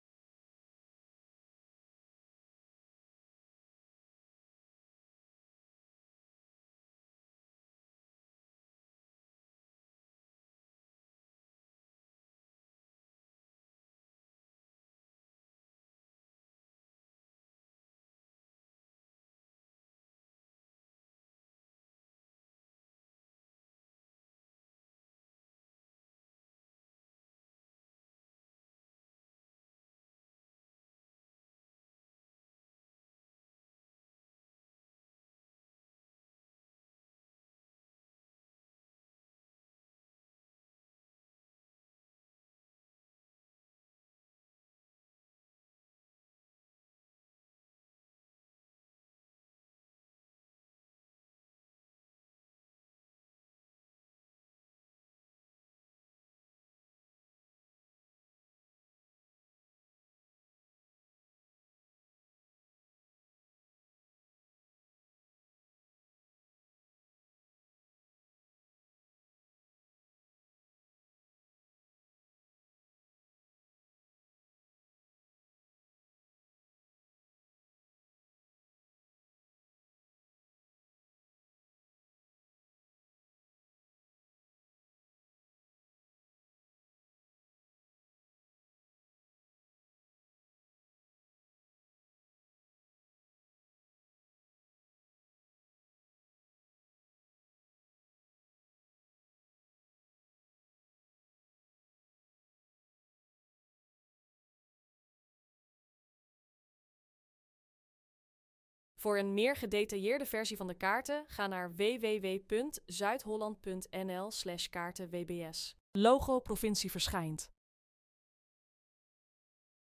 audiodescriptie_pzh_animatie-4_drinkwaterbeschikbaarheid.mp3